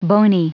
Prononciation du mot boney en anglais (fichier audio)